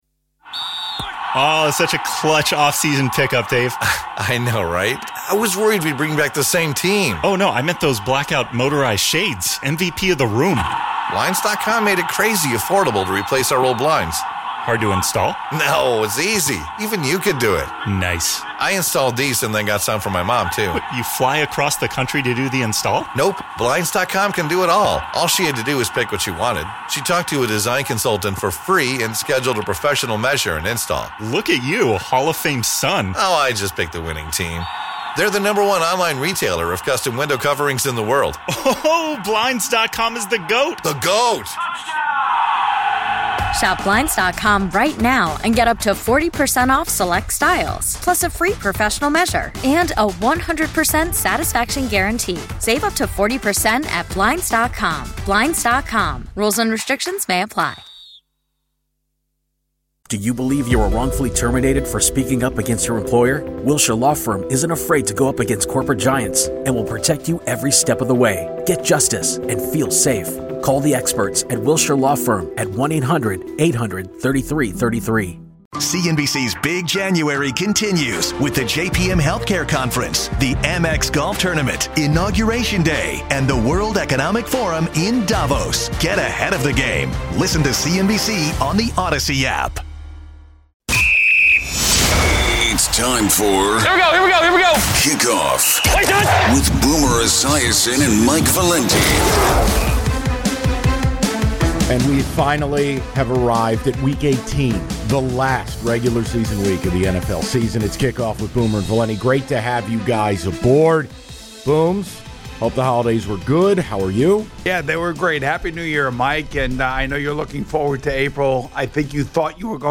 fun, smart and compelling Chicago sports talk with great listener interaction. The show features discussion of the Bears, Blackhawks, Bulls, Cubs and White Sox as well as the biggest sports headlines beyond Chicago.